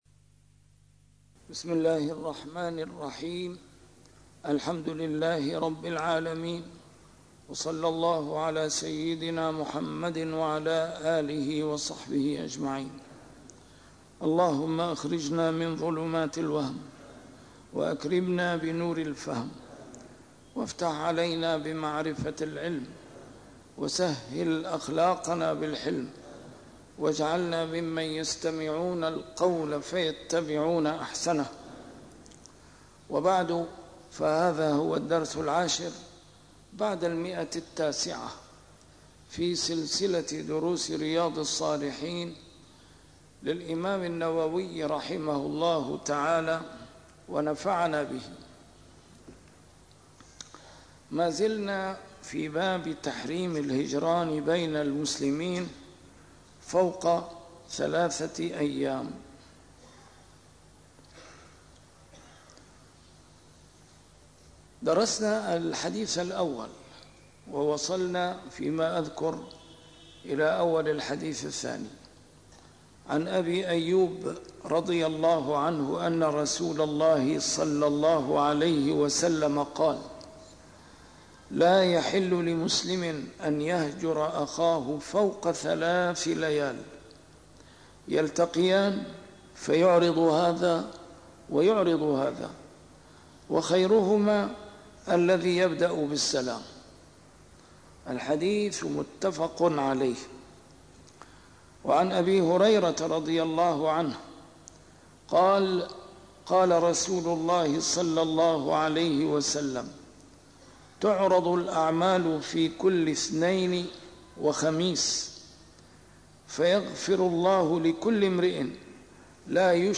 A MARTYR SCHOLAR: IMAM MUHAMMAD SAEED RAMADAN AL-BOUTI - الدروس العلمية - شرح كتاب رياض الصالحين - 910- شرح رياض الصالحين: تحريم الهجران بين المسلمين